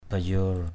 /ba-yo:r/ (d.) bông Bayor (một loại hoa văn thổ cẩm Chăm).